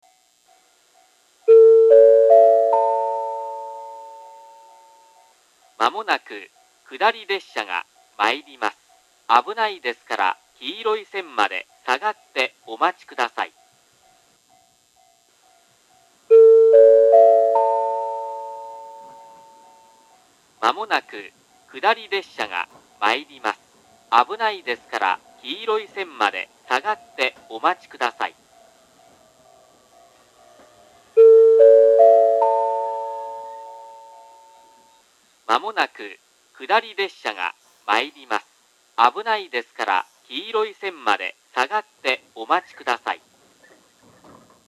１番線下り接近放送